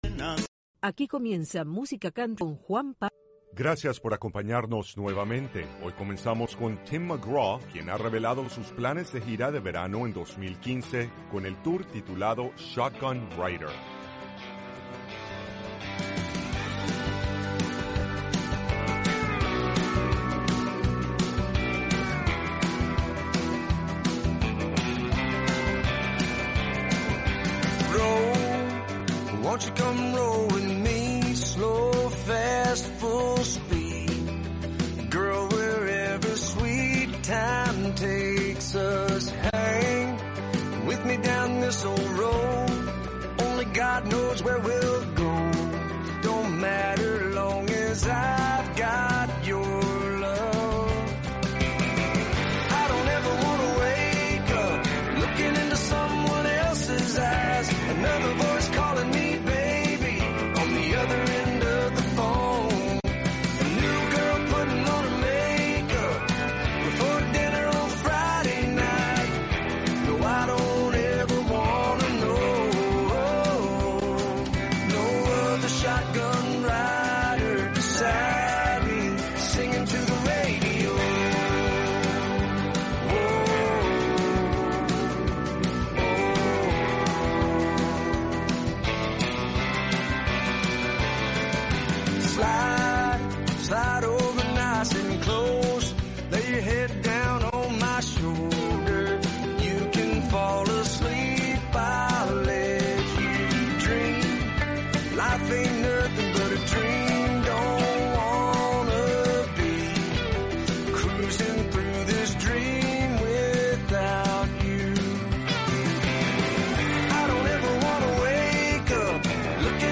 el programa musical